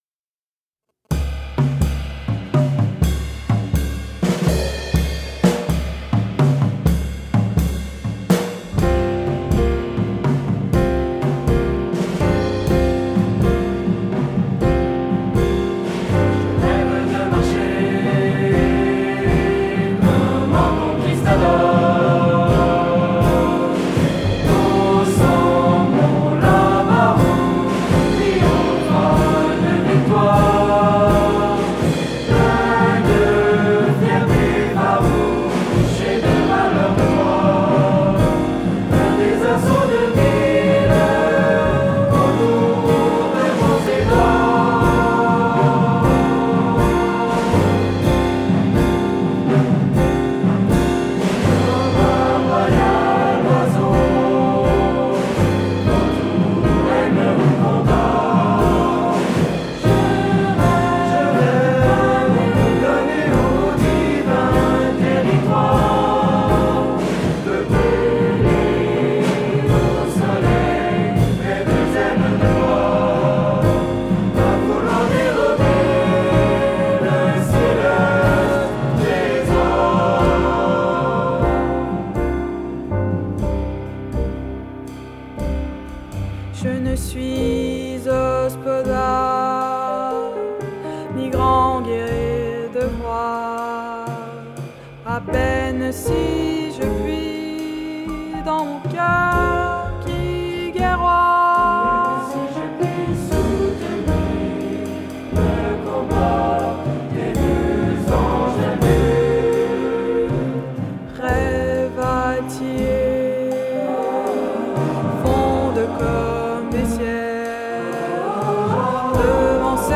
SATB – piano, basse & batterie